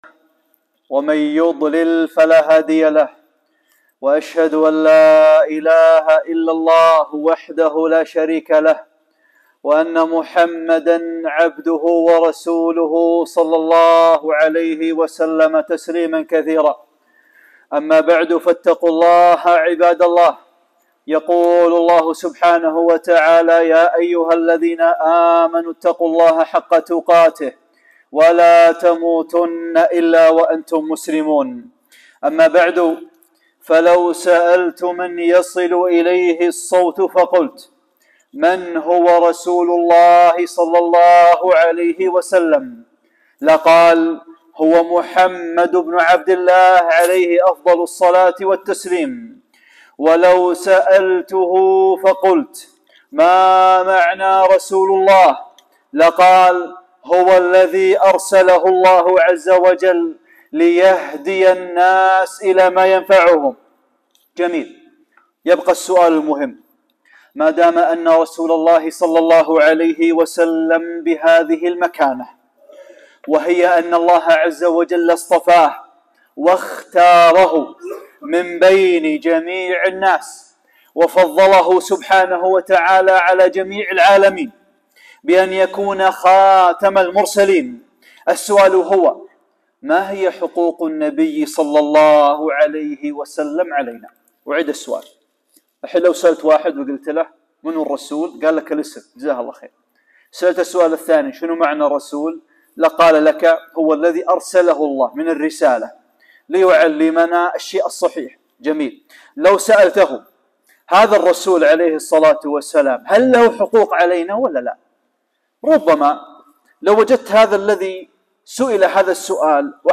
خطبة - (1) الإيمان بنبوة محمد ﷺ ورسالته | حقوق المصطفى ﷺ